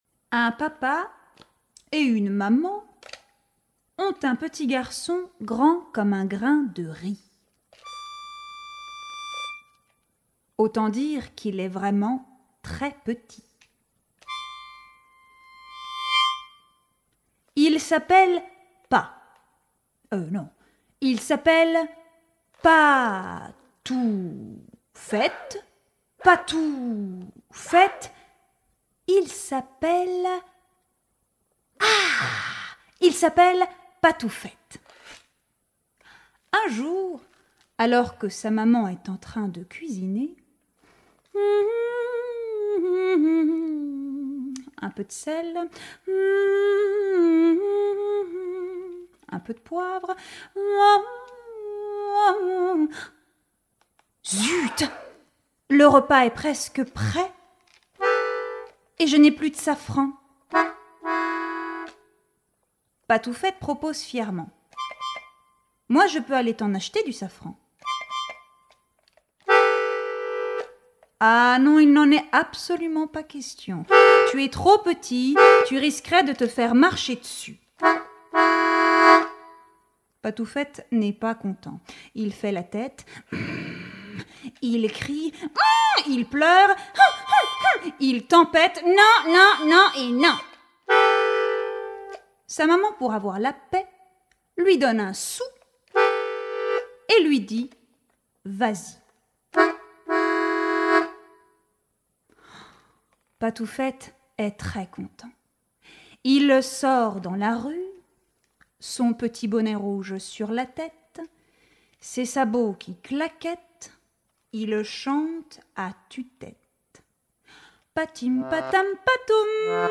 Spectacle pour 3/6 ans
écriture, jeu, chant, concertina, percussions et... bateaux en papier